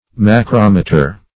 Search Result for " macrometer" : The Collaborative International Dictionary of English v.0.48: Macrometer \Ma*crom"e*ter\, n. [Macro- + -meter.] An instrument for determining the size or distance of inaccessible objects by means of two reflectors on a common sextant.